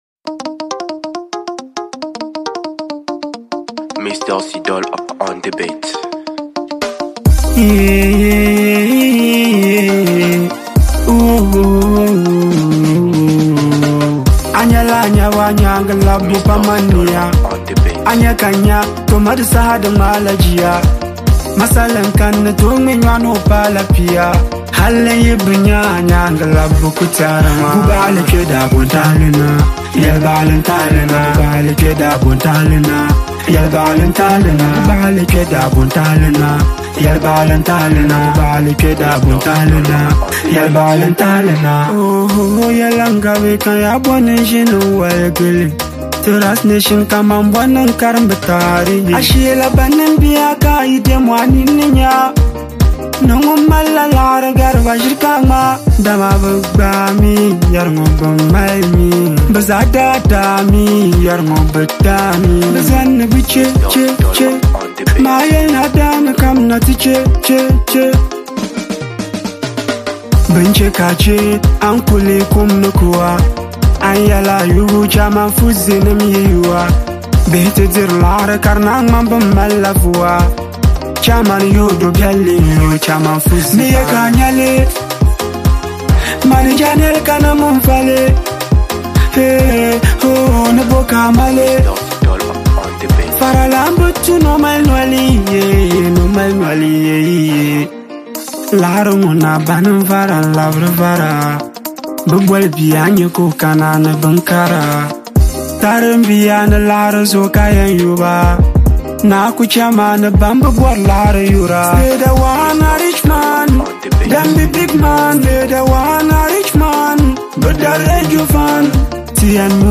With its engaging rhythm and meaningful message